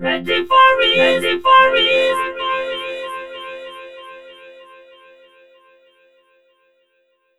READY4VOC -R.wav